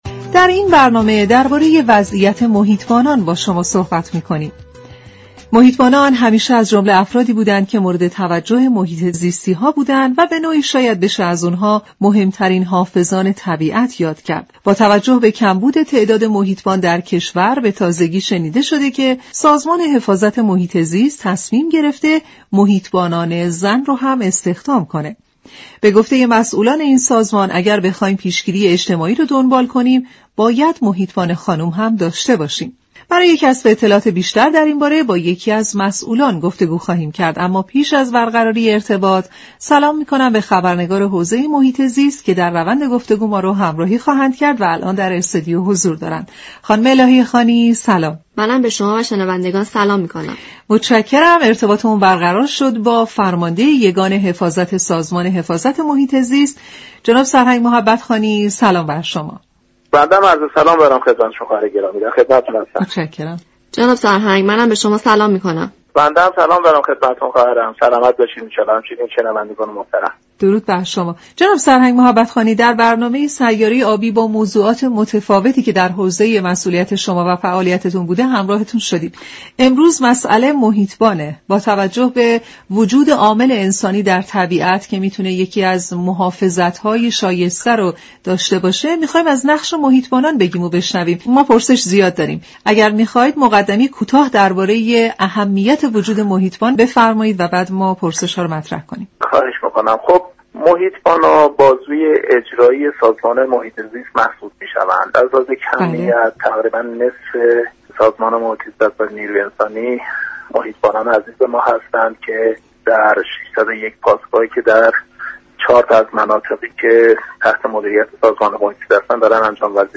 دریافت فایل سرهنگ محبت خانی فرمانده یگان حفاظت محیط زیست در گفت و گو با برنامه سیاره آبی در خصوص جذب محیط بان زن گفت: آنچه كه در این زمینه باید گفت آن است كه نوع فعالیت و سختی های كار در پاسگاه های محیط زیست امكان فعالیت را برای بانوان ناممكن می سازد. از این رو جذب محیط بان زن تنها در بخش آموزش انجام می گیرد.